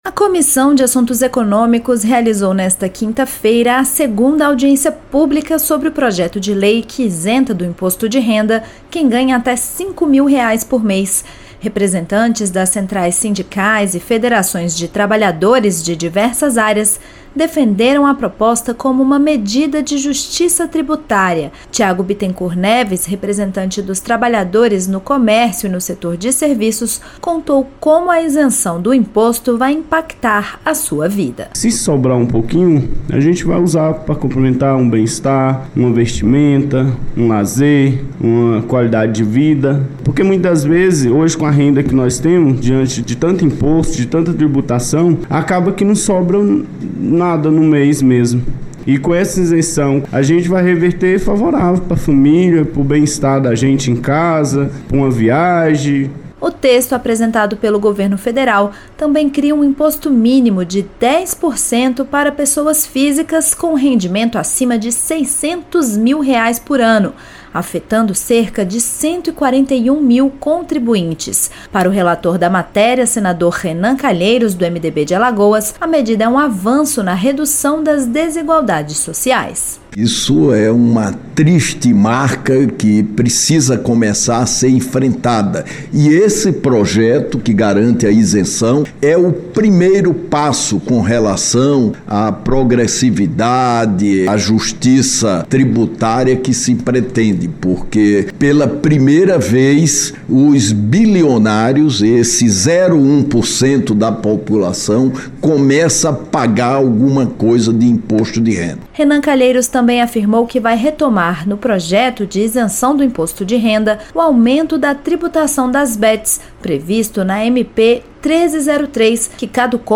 Representantes de centrais sindicais defenderam a proposta como forma de justiça tributária. O relator, Renan Calheiros (MDB-AL), disse que a medida reduz desigualdades e inclui os mais ricos na tributação.